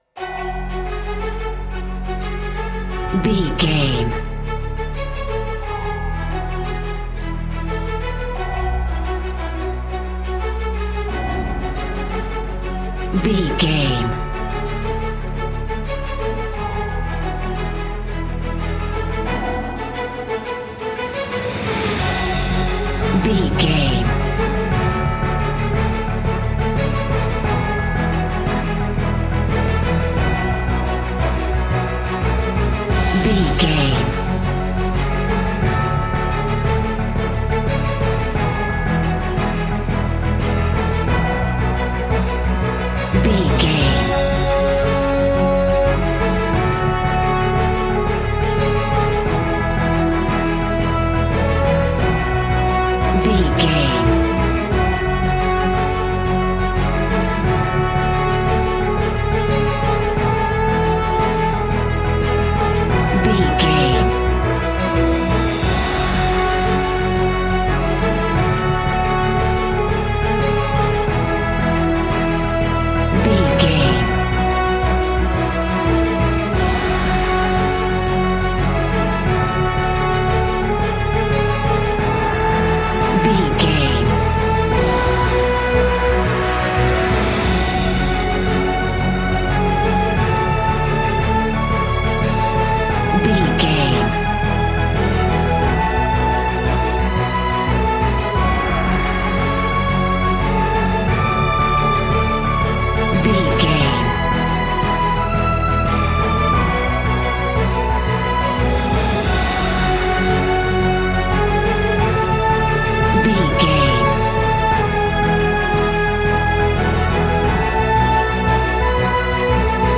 Epic / Action
Aeolian/Minor
F♯